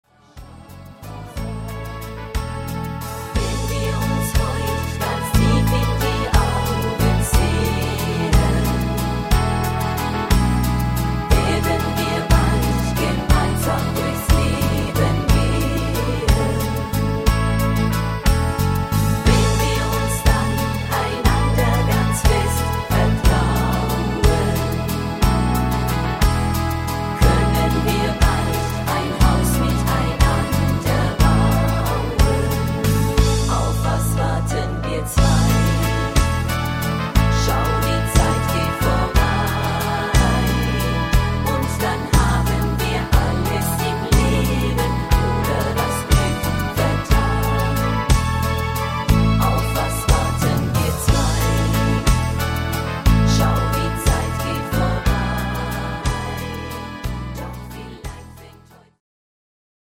Rhythmus  Waltz